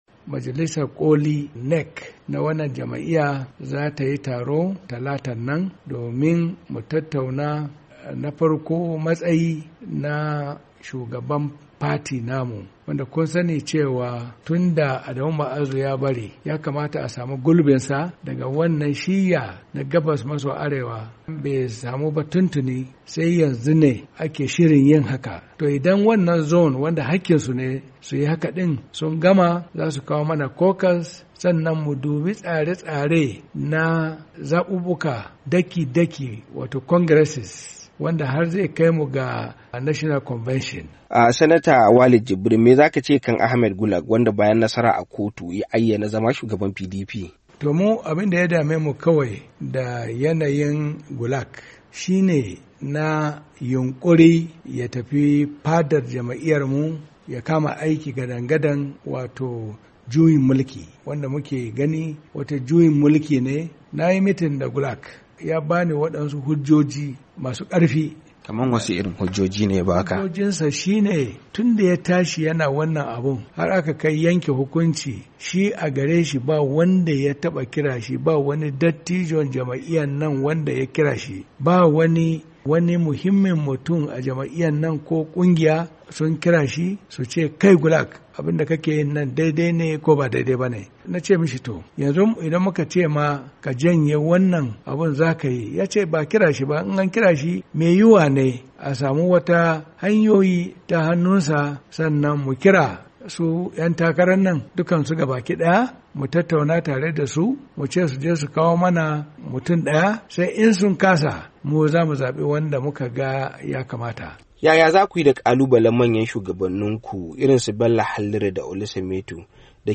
Majalisar Koli ta Jam’iyyar PDP za tayi taro ranar Talata mai zuwa domin tattaunawa kan matsayin shugaban jam’iyyar, domin maye gurbin Adamu Mu’azu daga shiyyar Arewa maso Gabas. A cewar Sanata Walid Jibrin a wata hira da wakilin Muryar Amurka.